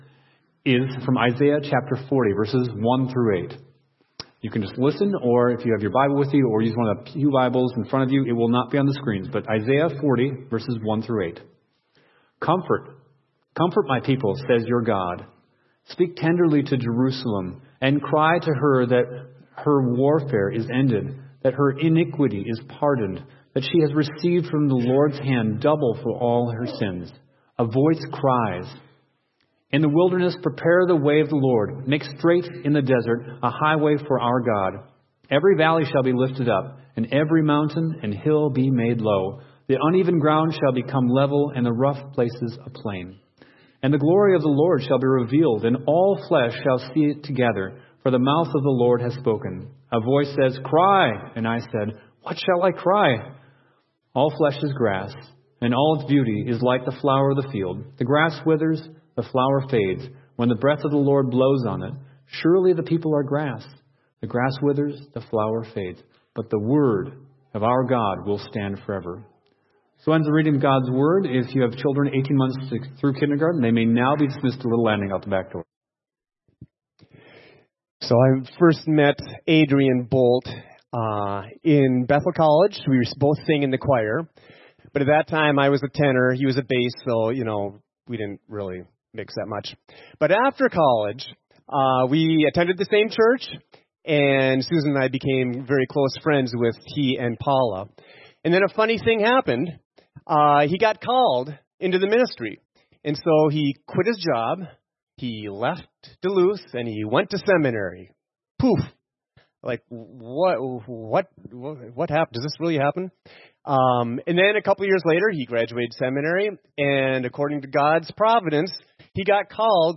Sermons | The Landing Church
Guest Preacher